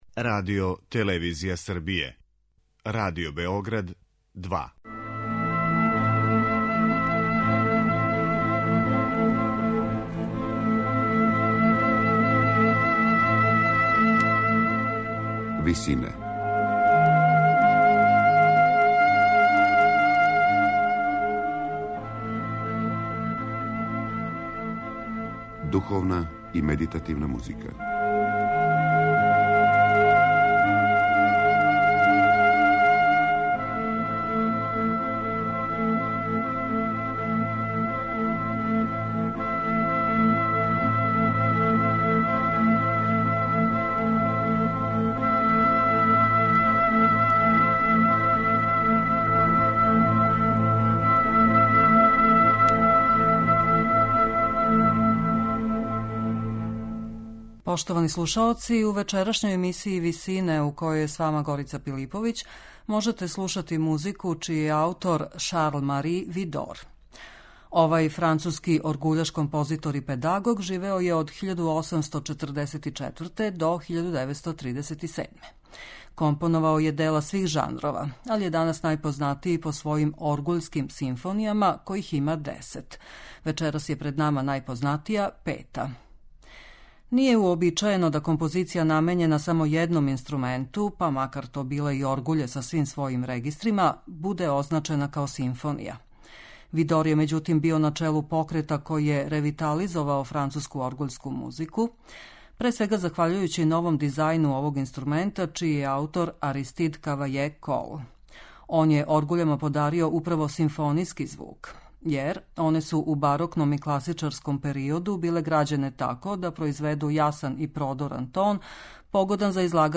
Шарл-Мари Видор: Пета оргуљска симфонија
На крају програма, у ВИСИНАМА представљамо медитативне и духовне композиције аутора свих конфесија и епоха.